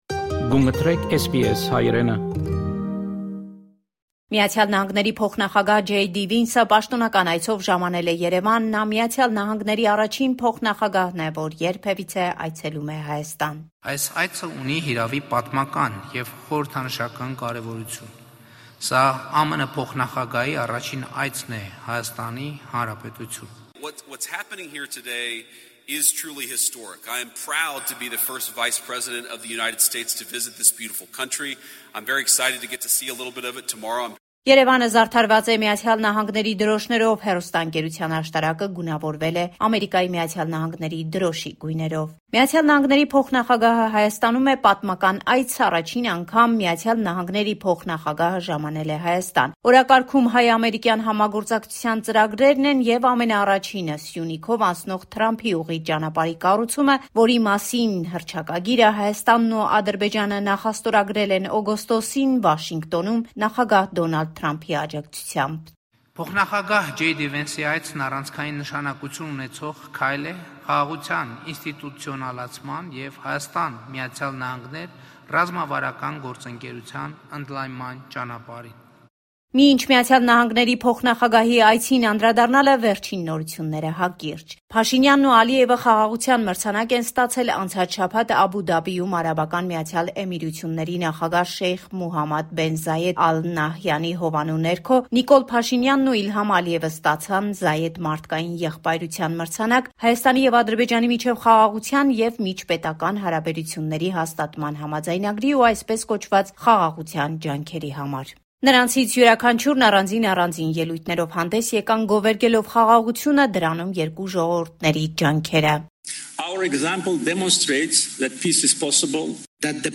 Latest news from Armenia, Artsakh and the Diaspora